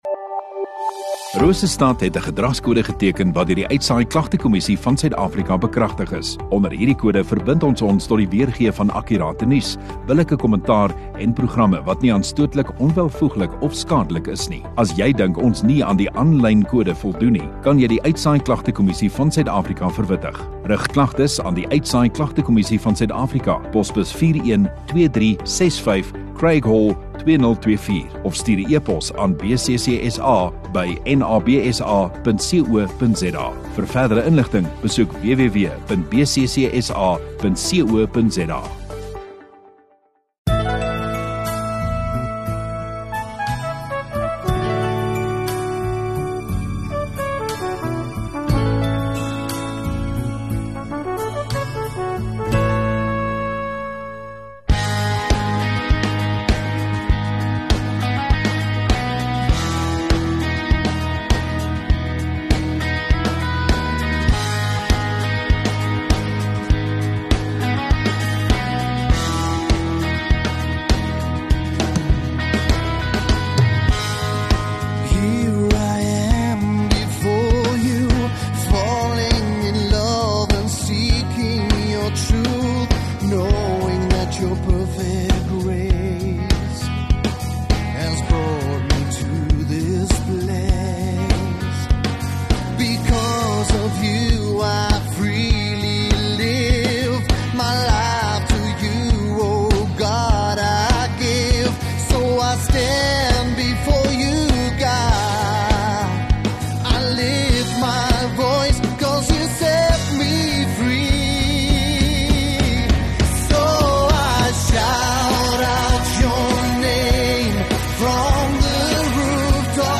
21 Jul Sondagoggend Erediens